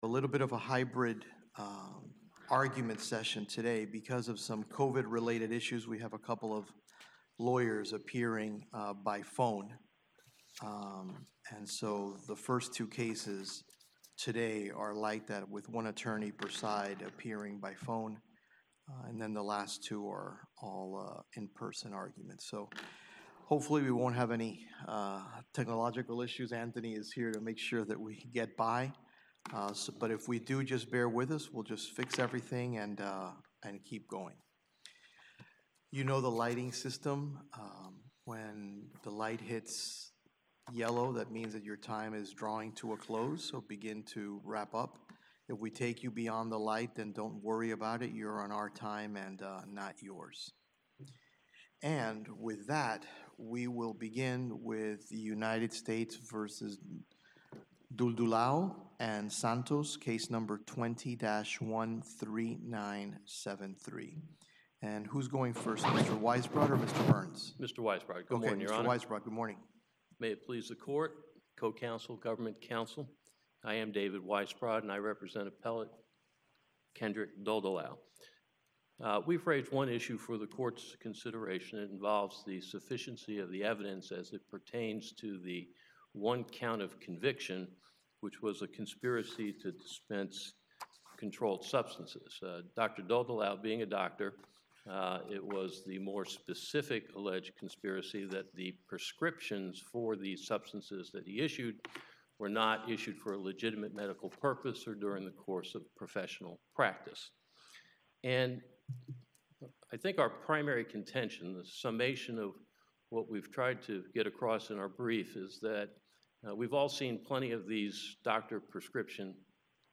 Oral Argument Recordings | Eleventh Circuit | United States Court of Appeals